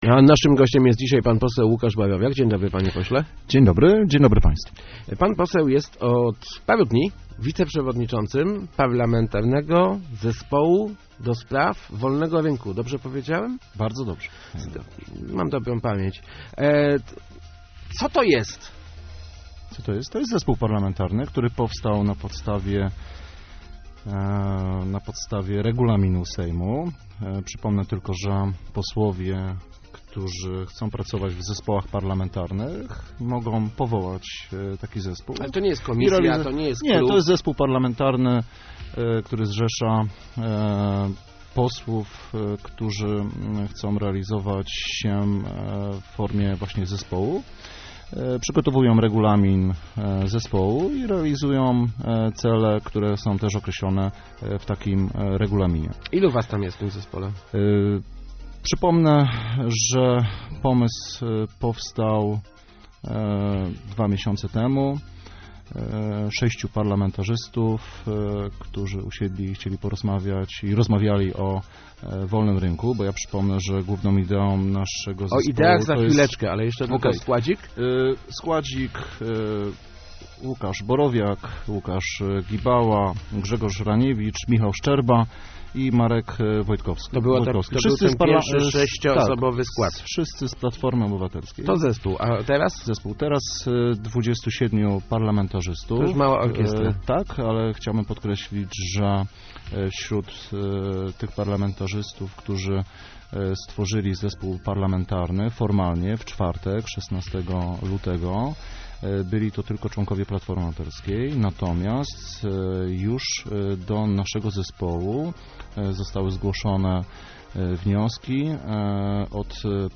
Kryzys to najlepszy czas, żeby rozmawiać o obniżeniu podatków - mówił w Rozmowach Elki poseł Łukasz Borowiak, wiceprzewodniczący Parlamentarnego Zespołu ds Wolnego Rynku. Jak dodał, członkowie zespołu są poważną siłą, z którą premier powinien się liczyć.